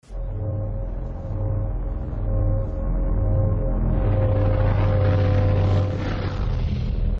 Der Sound des Shuttleantriebs